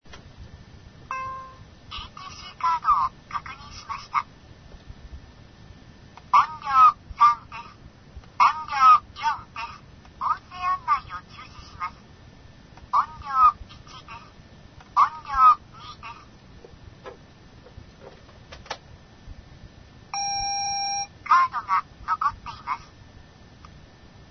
それなりのおねえさん声であるが、